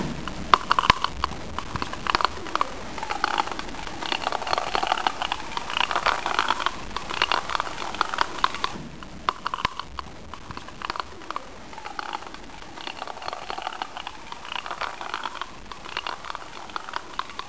› Knarren der Brunnenkette [WAV | 377 KB] und das
SLH_Brunnenkurbel.wav